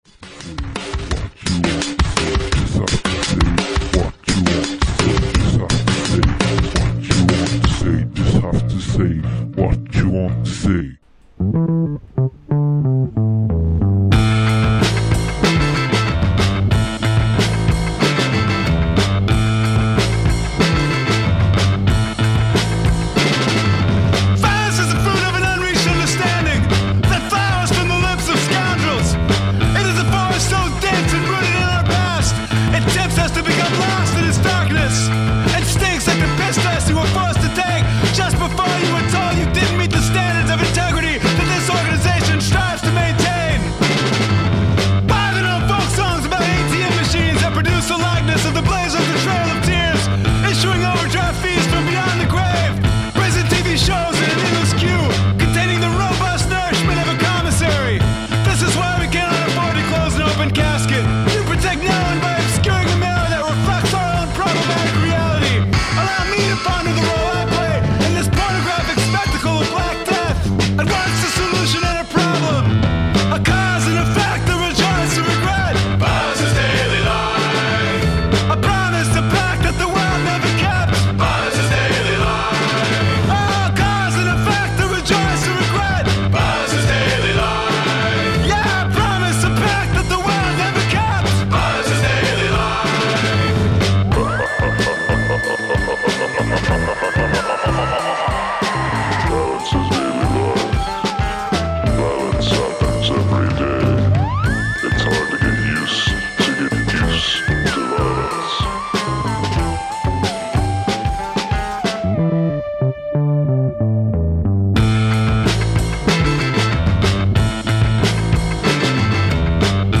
Extrait de l'Emission "Zack and Judy" sur Radio Saint Ferréol